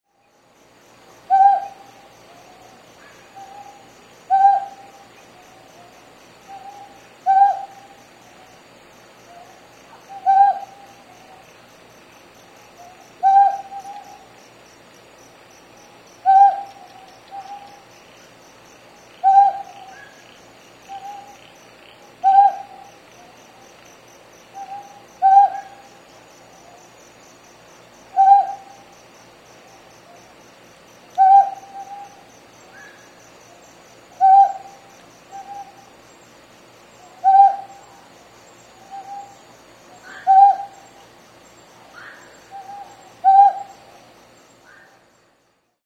ryukyukonohazuku_s1.mp3